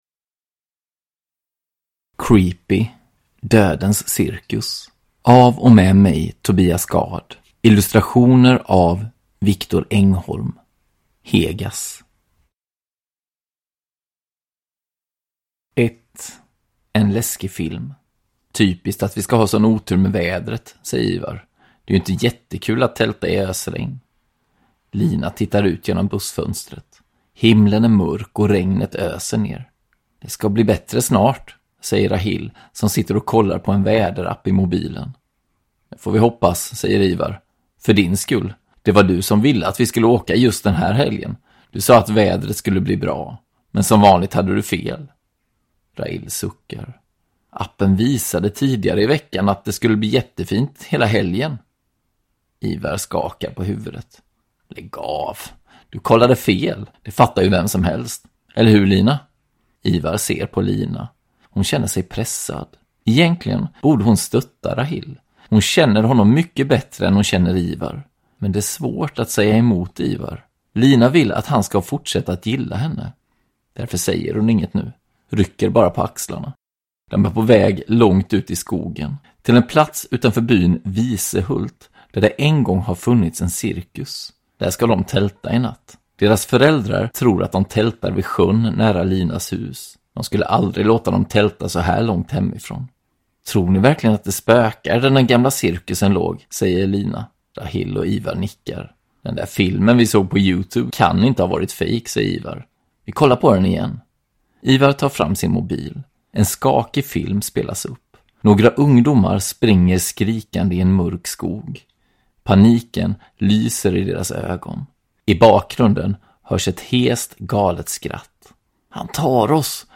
Dödens cirkus (ljudbok) av Tobias Gard